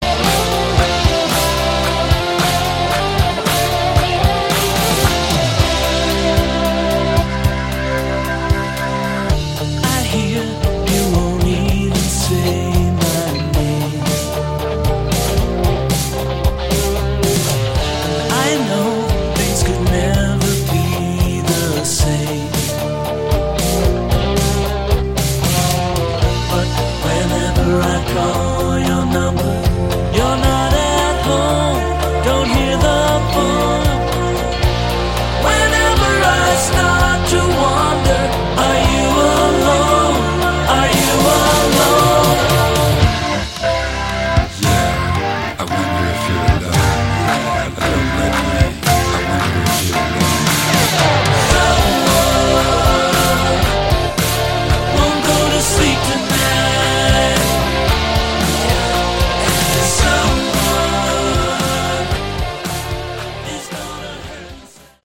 Category: AOR / Melodic Hard Rock